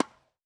Ball Hit Distant Tennis.wav